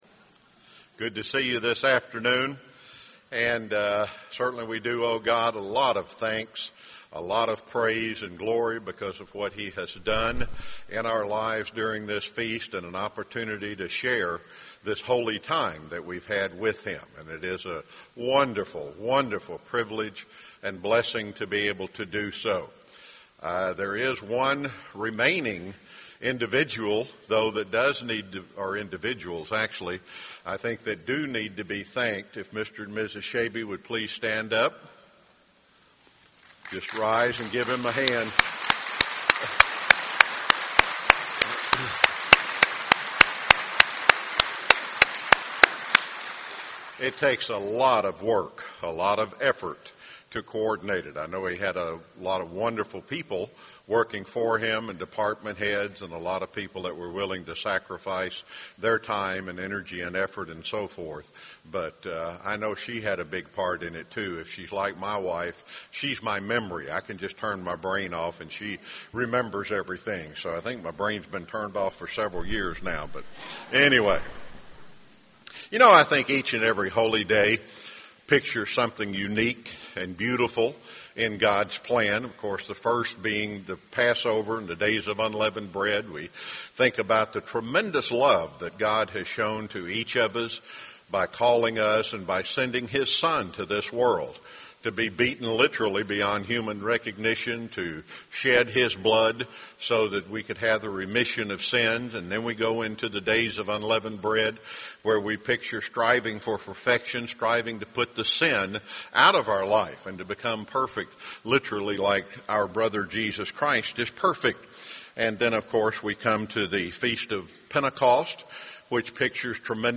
This sermon was given at the Jekyll Island, Georgia 2012 Feast site.